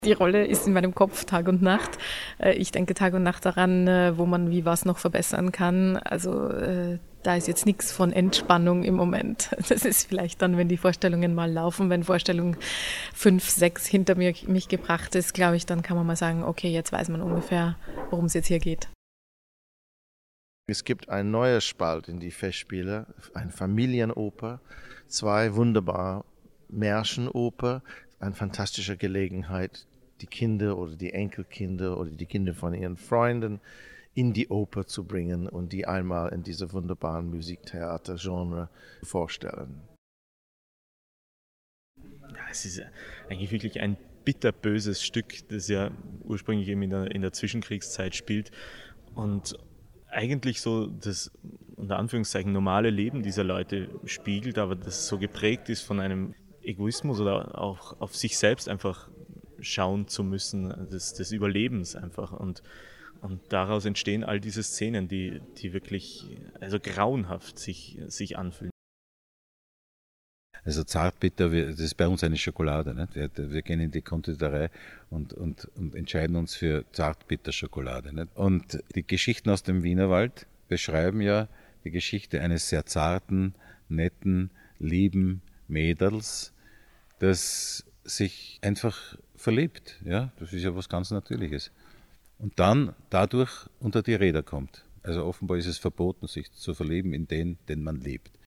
O-Ton Pressetag - Feature